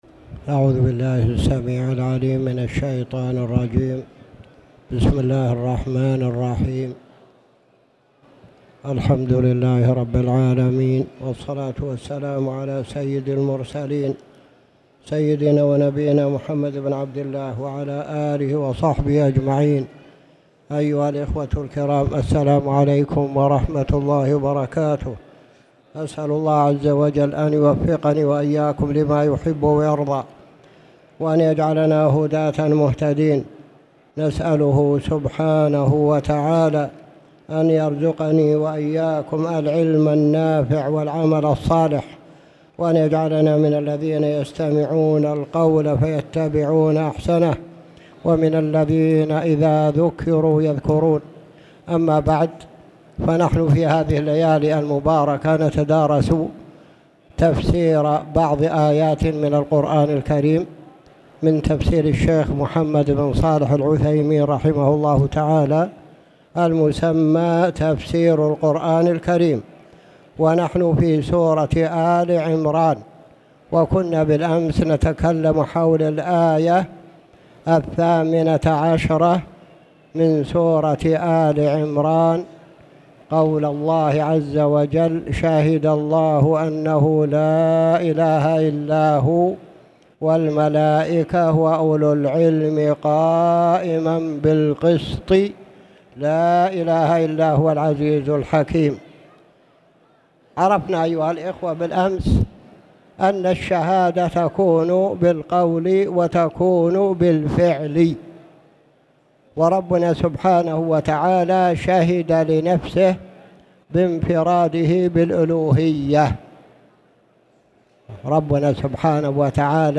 تاريخ النشر ٢٠ ربيع الثاني ١٤٣٨ هـ المكان: المسجد الحرام الشيخ